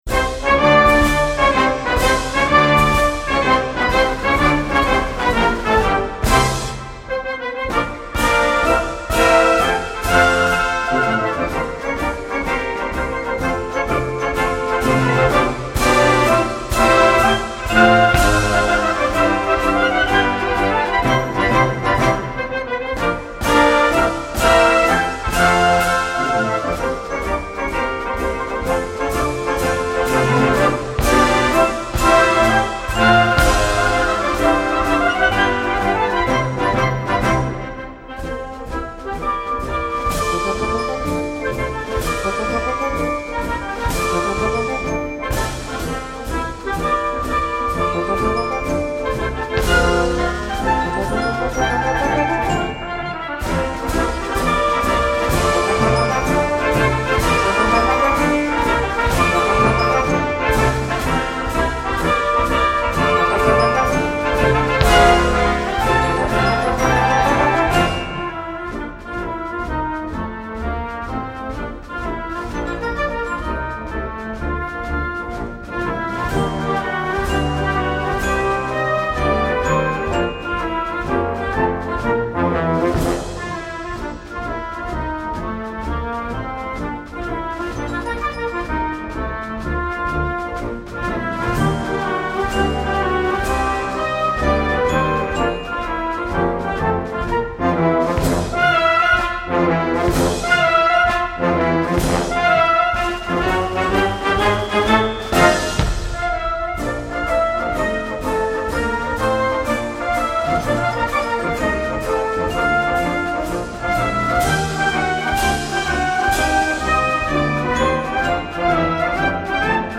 Gattung: Marsch
Besetzung: Blasorchester
lebhafter 6/8-Marsch